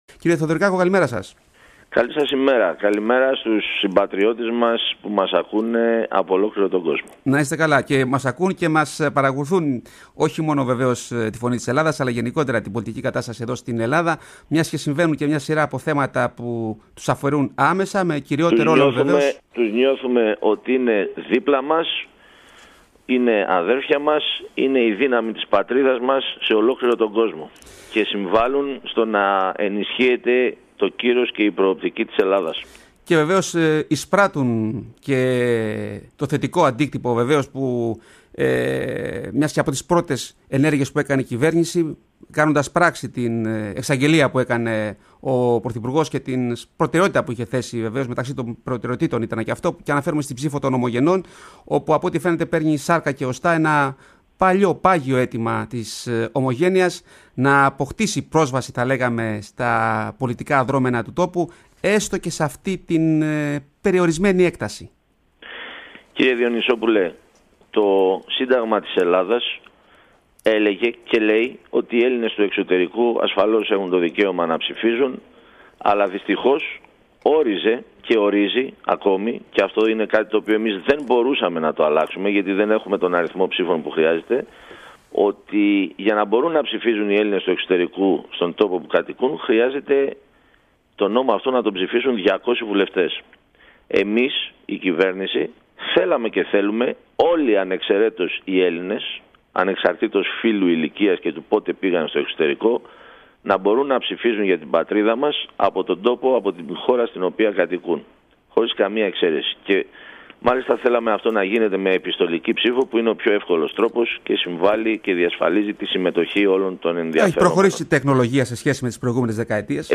Ακούστε τη συνέντευξη του κ. Θεοδωρικάκου: